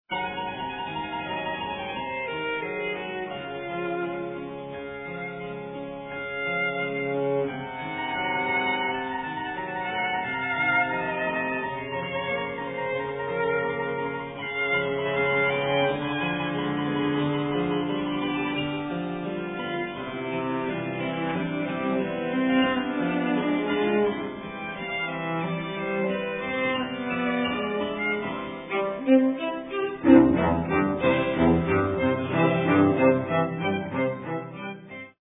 for Violin, Cello and Harpsichord